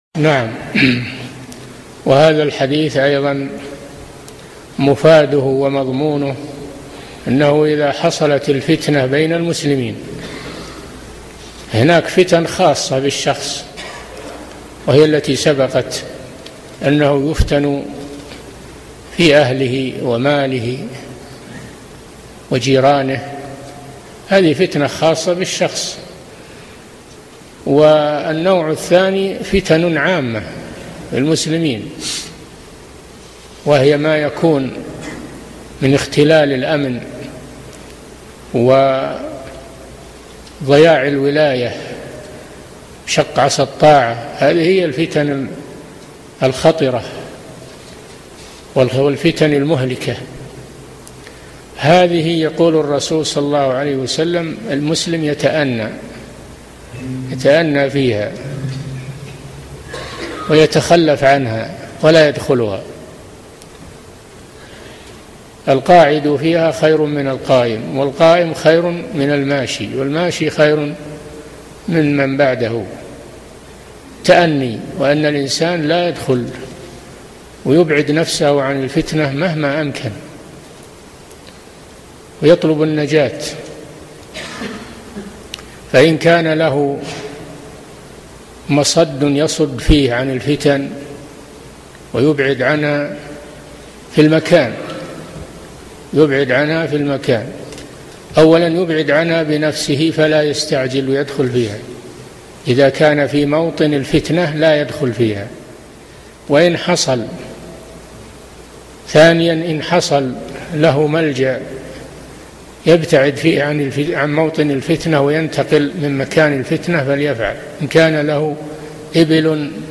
Download audio file Downloaded: 645 Played: 2252 Artist: العلامة صالح الفوزان حفظه الله Title: شرح حديث تكون فتنة القاعد فيها خير من القائم Album: موقع النهج الواضح Length: 5:27 minutes (5.01 MB) Format: MP3 Stereo 44kHz 128Kbps (VBR)